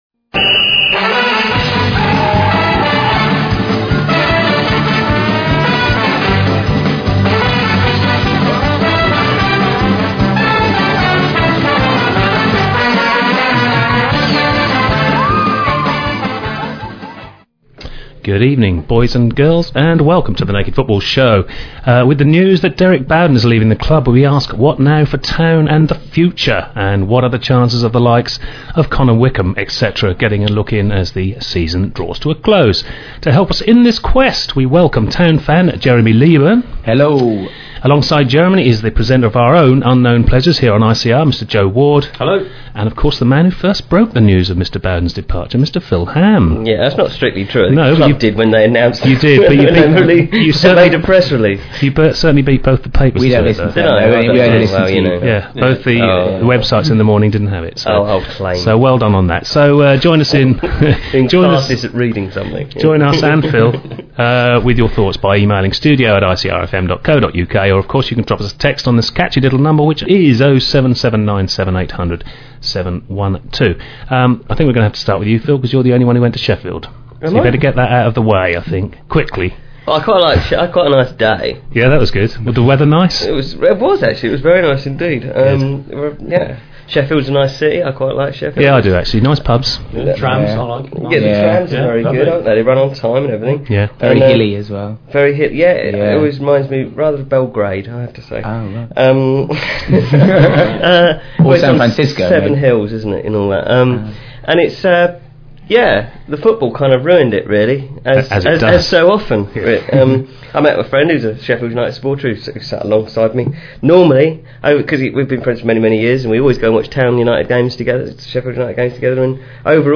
The programme goes out live at 6pm every Wednesday on Ipswich Community Radio at 105.7FM if in the Ipswich area or online if not.